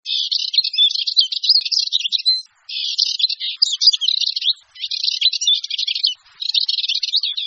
En cliquant ici vous entendrez le chant de la Mésange noire